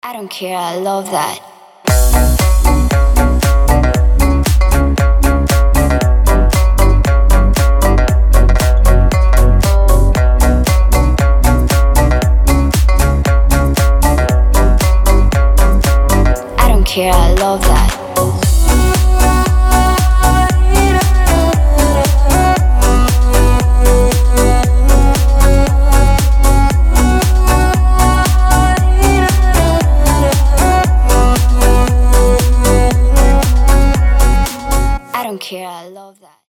• Качество: 320, Stereo
гитара
громкие
заводные
Dance Pop
house